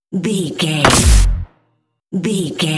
Dramatic hit deep electricity
Sound Effects
heavy
intense
dark
aggressive
hits